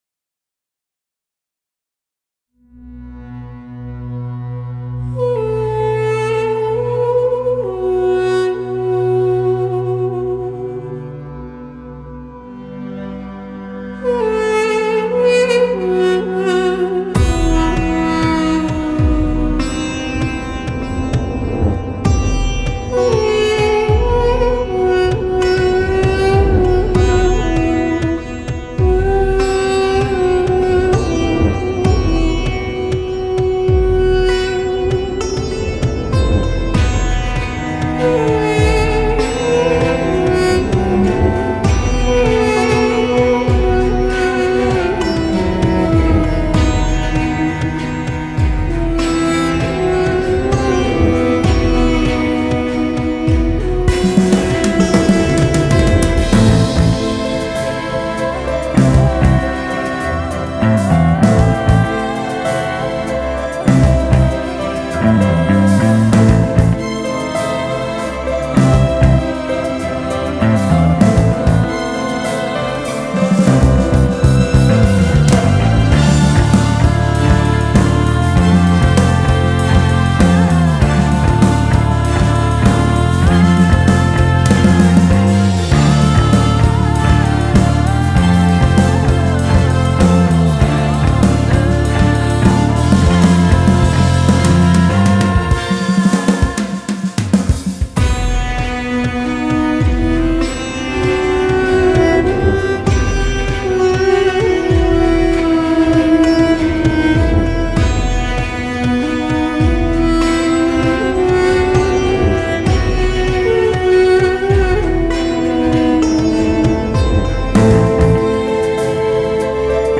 synth
вокал
bass
duduk